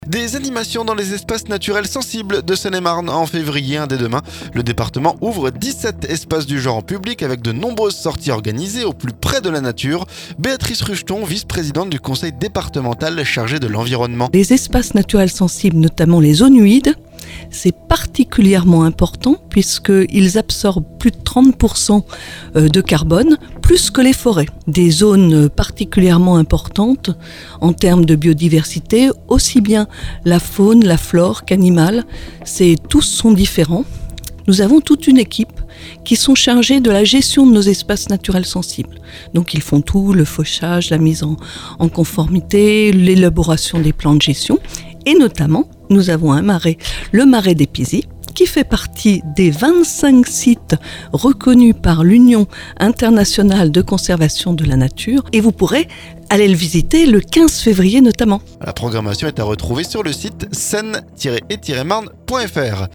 Avec de nombreuses sorties organisées, au plus près de la nature. Béatrice Rucheton, vice-présidente du Conseil départemental chargée de l'environnement.